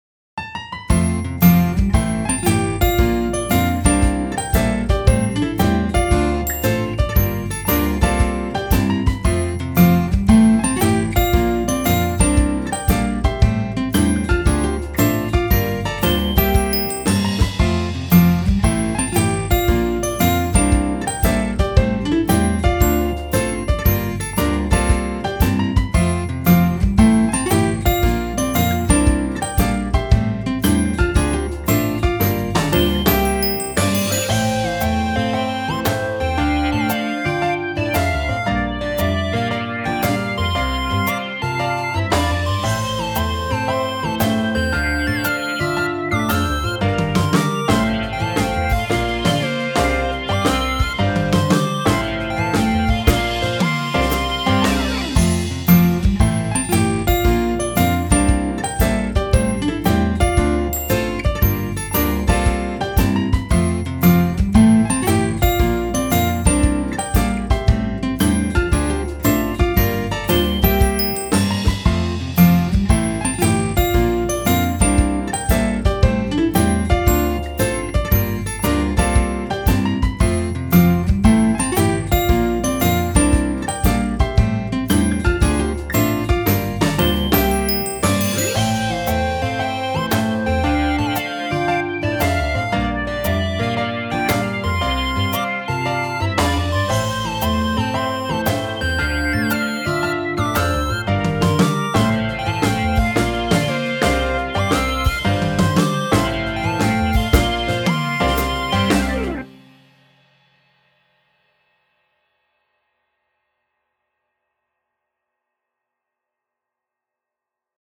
大好きな人とまったりした時間を過ごしている、そんなイメージです。